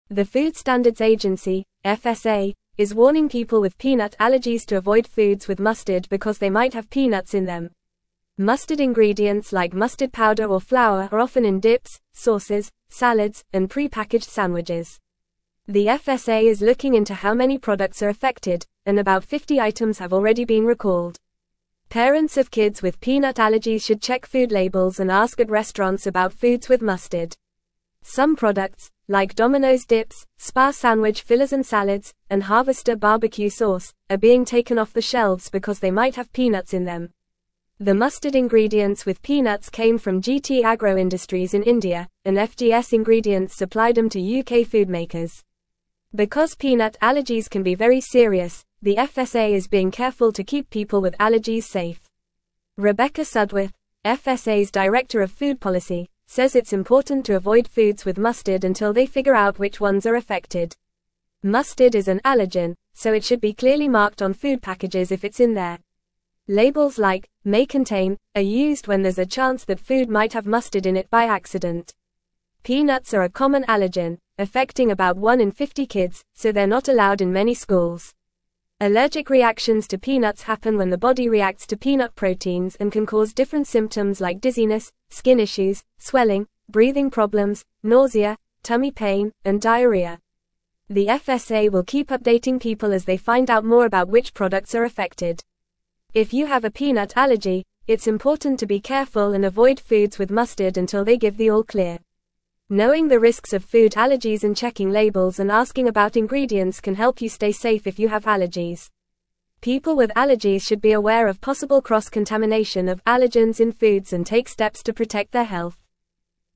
Fast
English-Newsroom-Upper-Intermediate-FAST-Reading-FSA-Warns-of-Peanut-Contamination-in-Mustard-Products.mp3